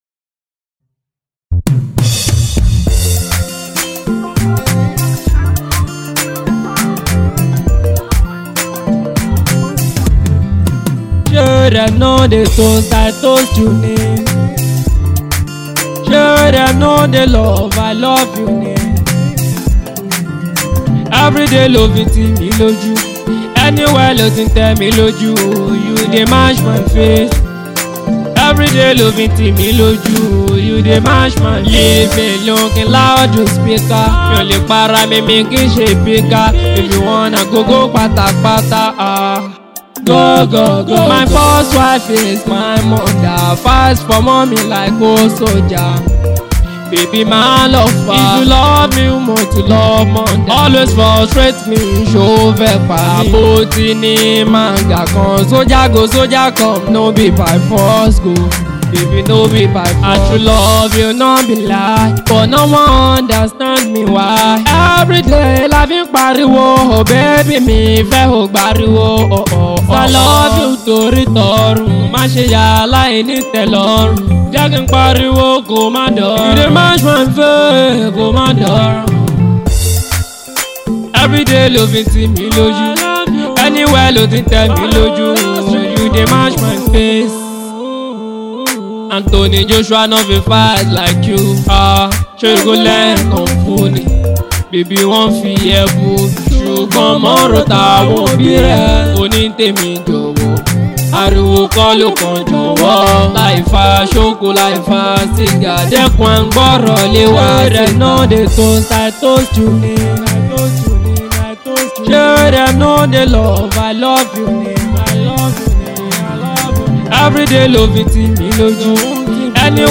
afro